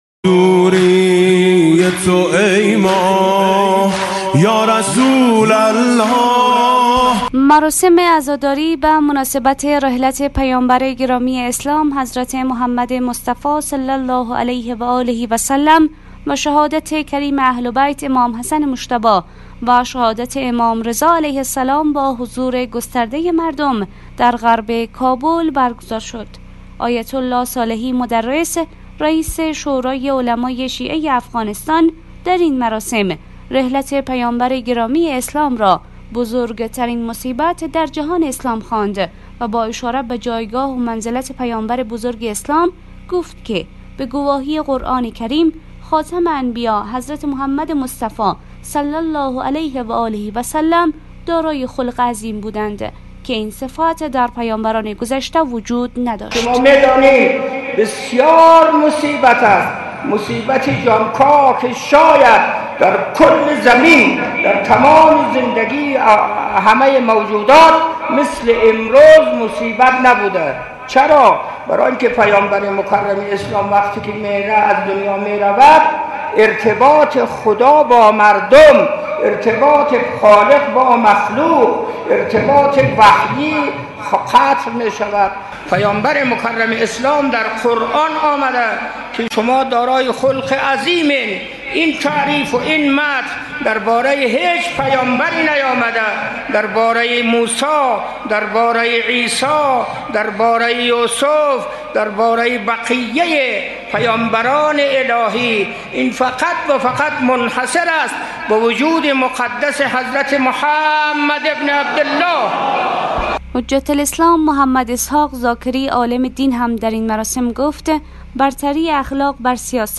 سخنرانان مراسم‌ رحلت نبی مکرم اسلام (ص) و امام حسن مجتبی (ع) در غرب کابل بر توجه به اخلاق و تعلیم و تربیت در جامعه به عنوان سیره عملی معصومین (ع) تأکید کرد...